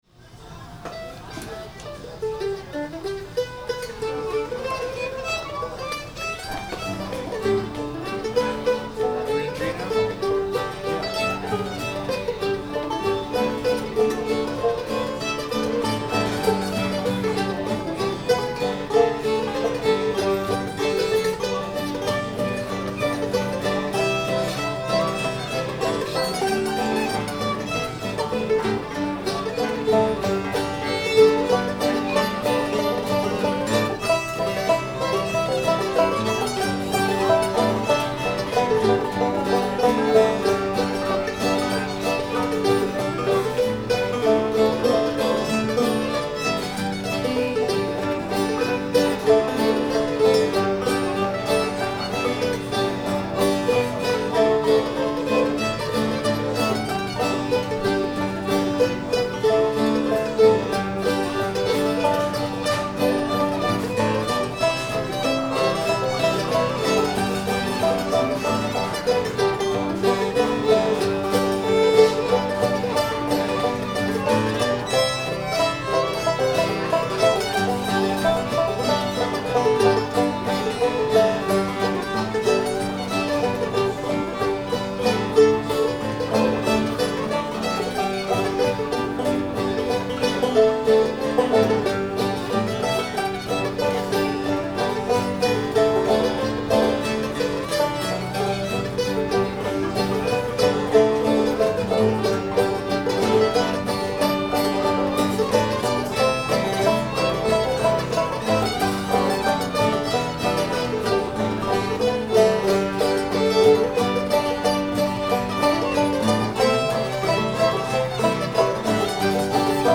cuffy [G]